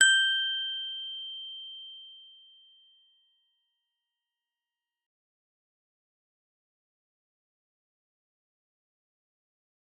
G_Musicbox-G6-f.wav